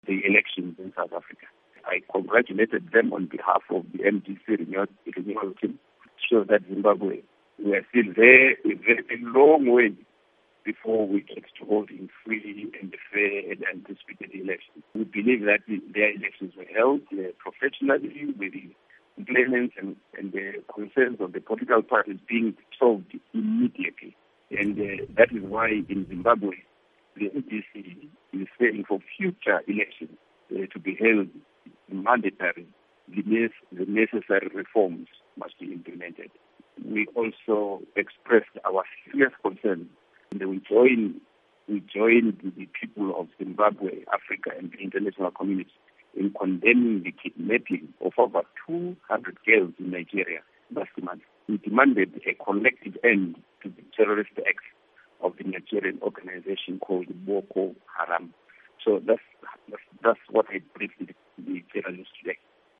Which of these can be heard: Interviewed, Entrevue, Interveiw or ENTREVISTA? Interveiw